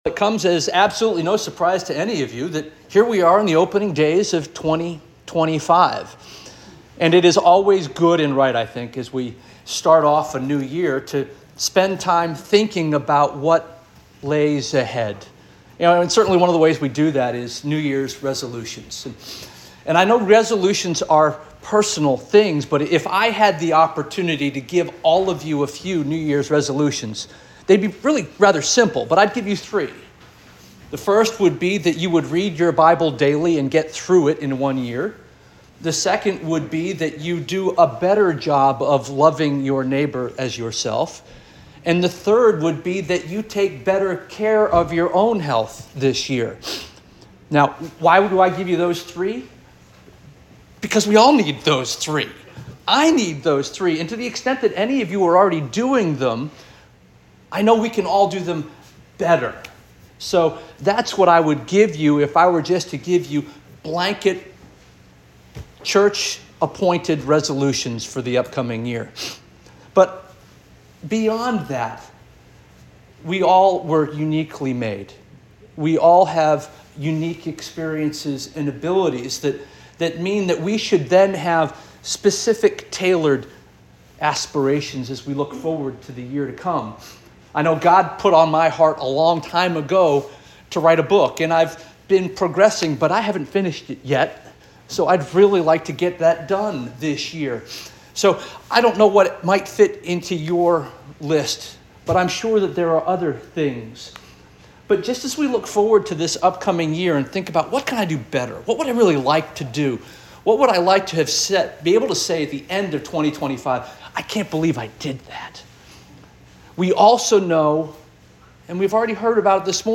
January 5 2025 Sermon